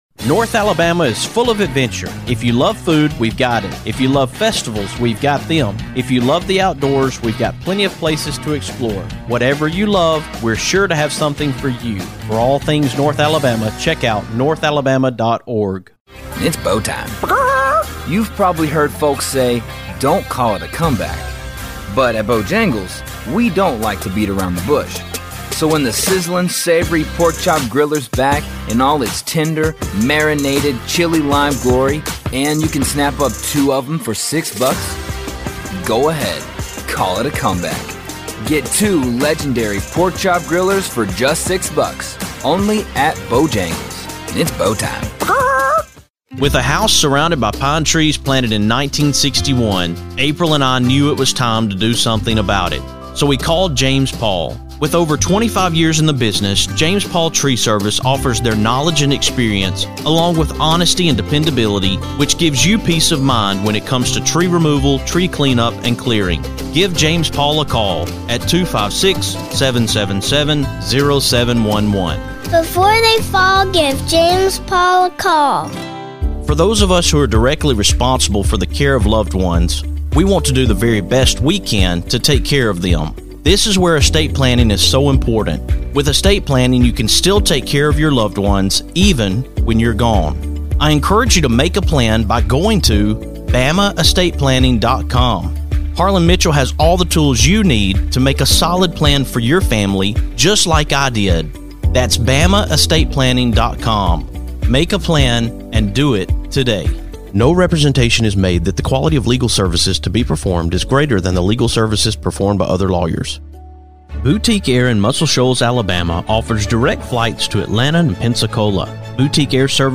On this edition of TMWS, I am coming to you from the Down Syndrome Alabama Step Up for Down Syndrome Walk in Tuscaloosa!